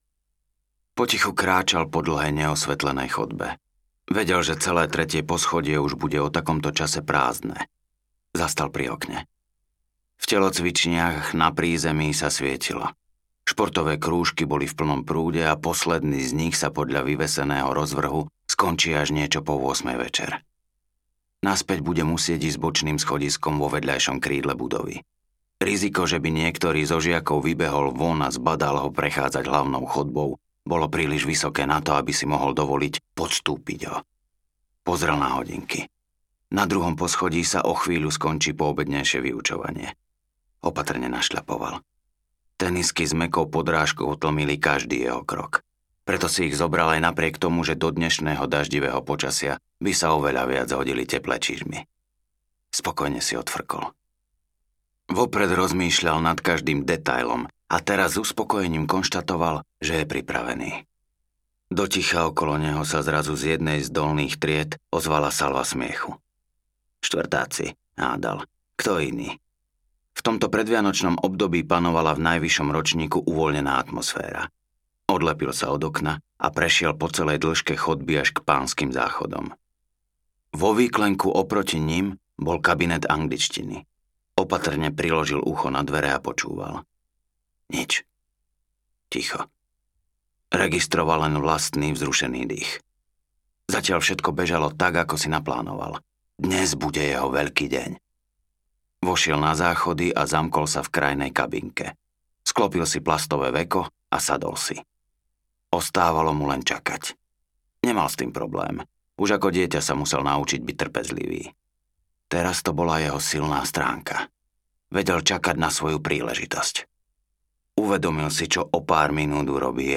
Nenapravený omyl audiokniha
Ukázka z knihy